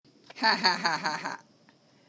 Annoying Laugh Sound Button - Free Download & Play